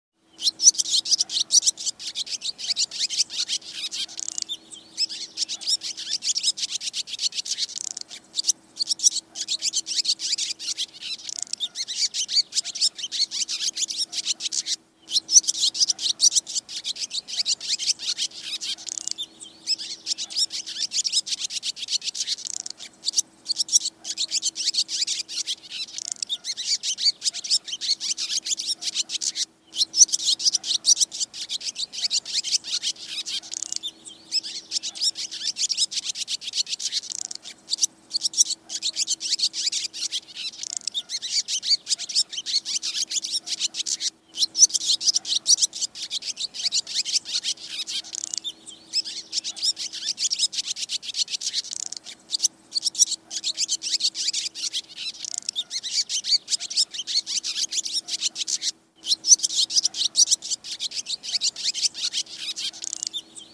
Tiếng Én kêu MP3